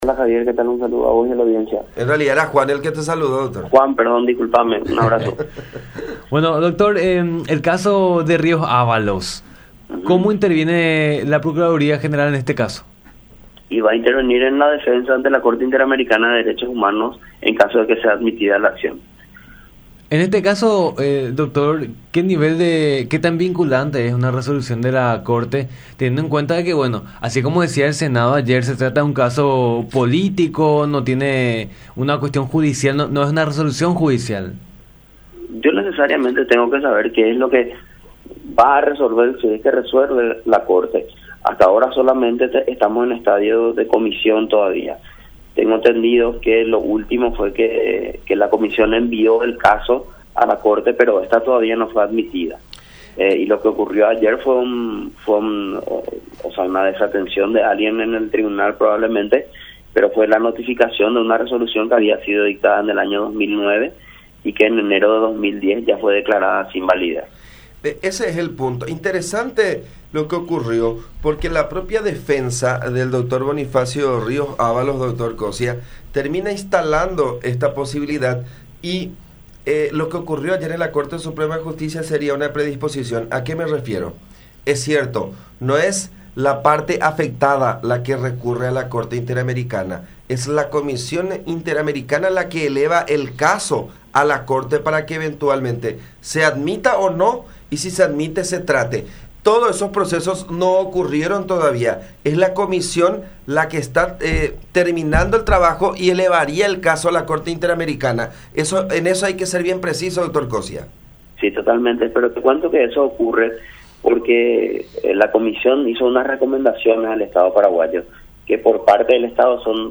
“Se produjo una desatención de alguien en el tribunal, ya que fue la resolución de una notificación del año 2009 que en enero del 2010 fue declarada sin validar”, explicó Coscia en contacto con La Unión.